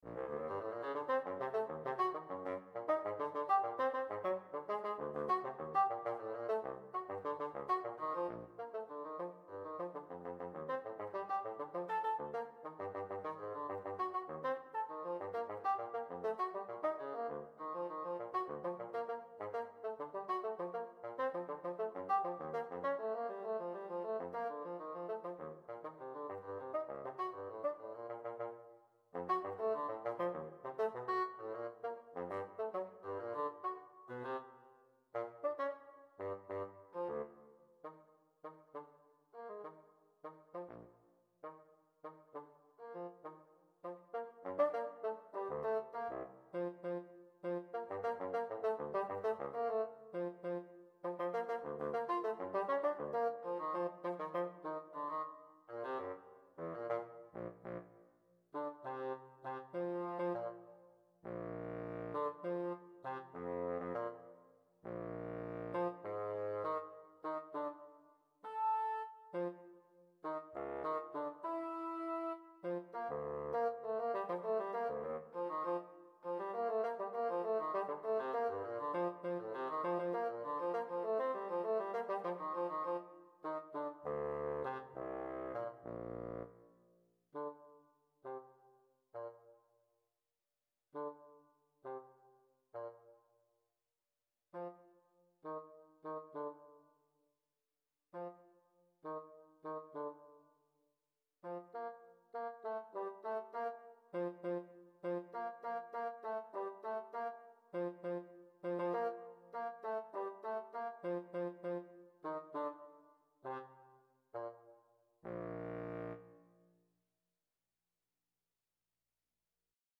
mp3 (computer generated)